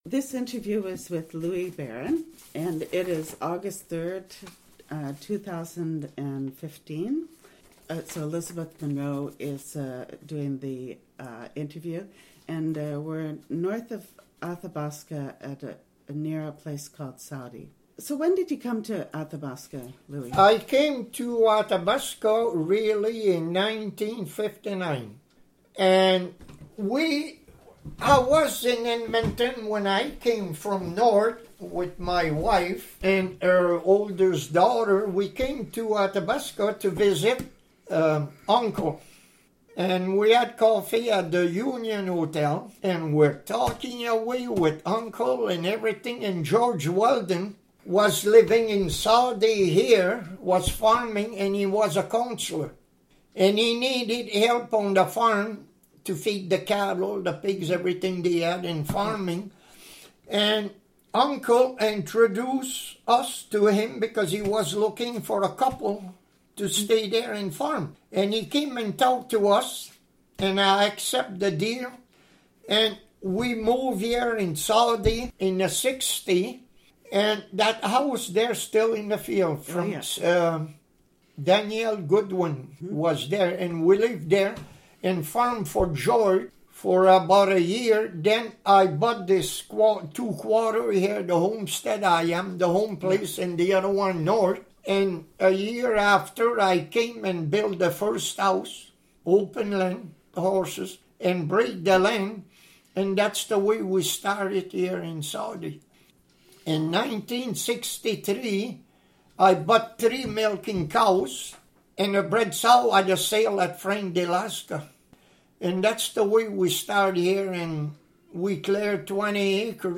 Audio interview, transcript of audio interview and video,